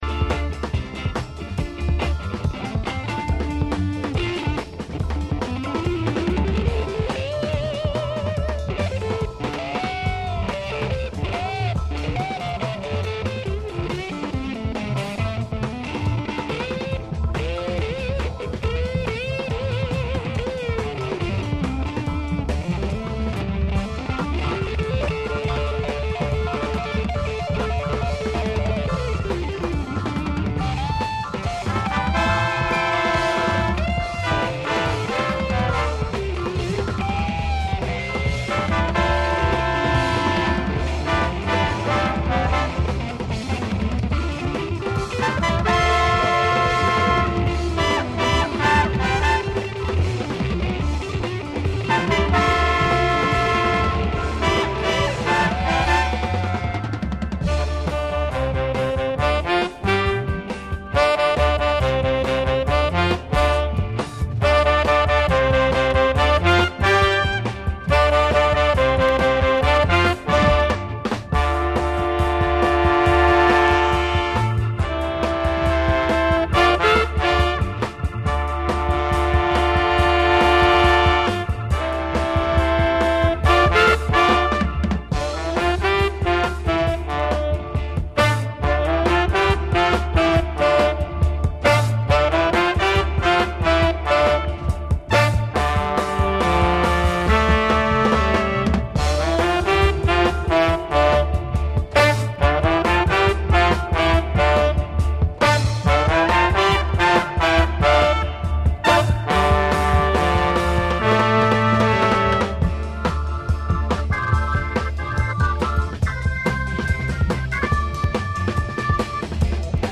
Three mad psyche pop organ grooves on this